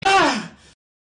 Play, download and share Ahh! Reaction original sound button!!!!
for-honor-hihglander-audiotrimmer.mp3